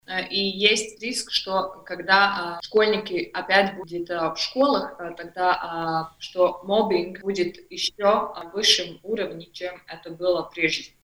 В эфире радио Baltkom сегодня обсуждали темы: пустые офисы из-за «удаленки» , «зеленая» Латвия, травля в школе.